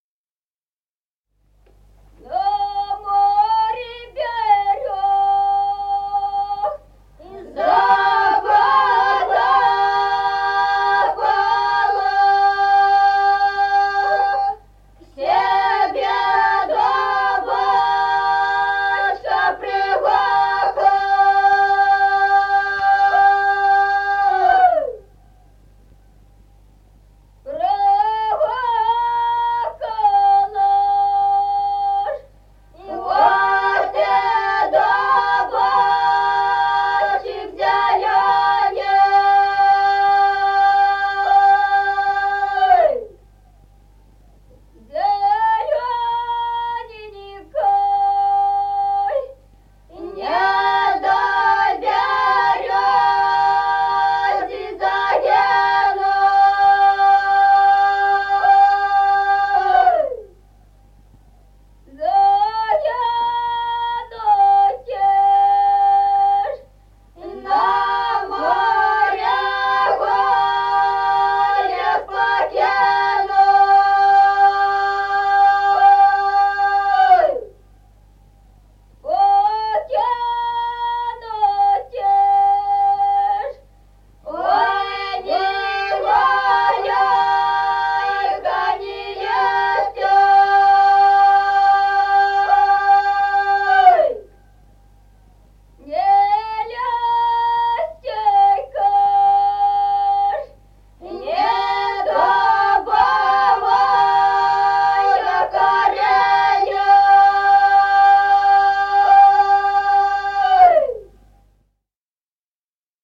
Музыкальный фольклор села Мишковка «На море берёза потопала», весновая.